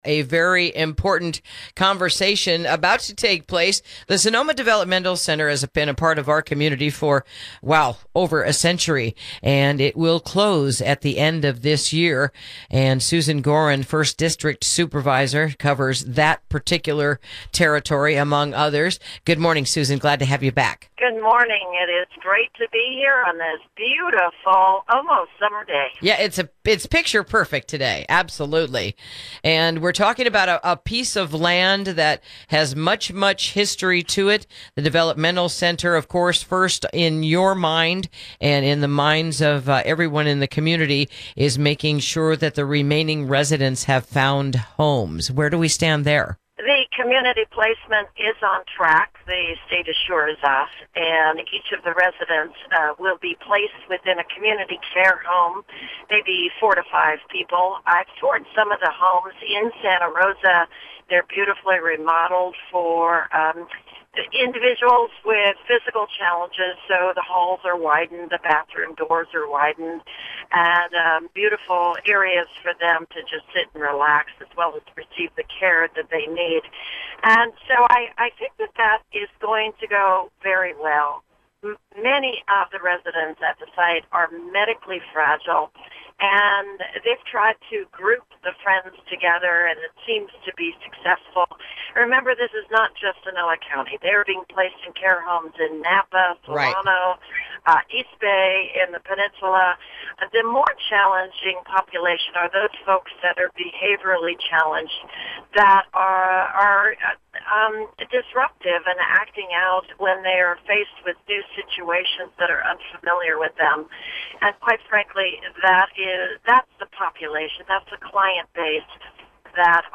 Interview: Progress Moving Forward on the Sonoma Development Center Property